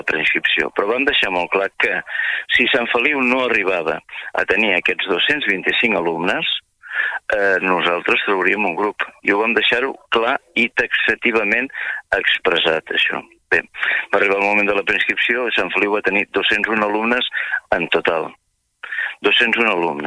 Entrevistem al Supermatí a Martí Fonalleras, director territorial a Girona del Departament d'Ensenyament, que descarta les demandes dels pares i mares de les escoles